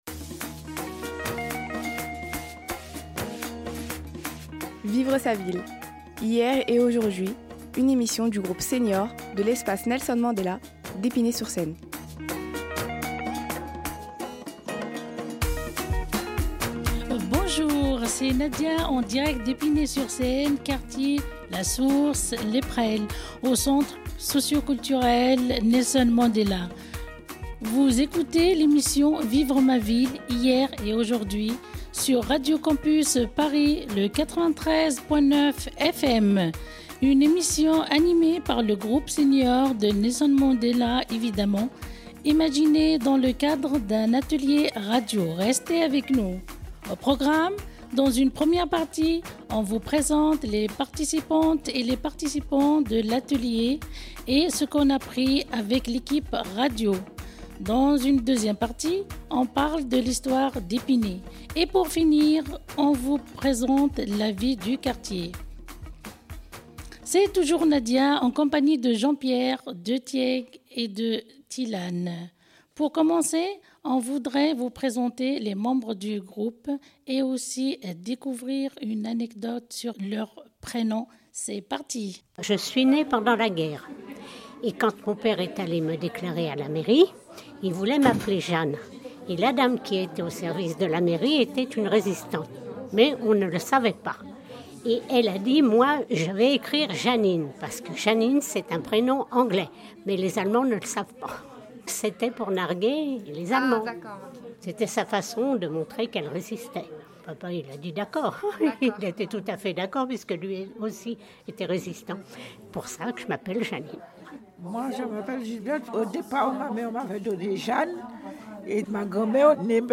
A partir d'échanges, de balades sonores dans le quartier et d'interviews, le groupe a imaginé et animé une émission de radio enregistrée en public à l'Espace Nelson Mandela le 19 juillet 2023.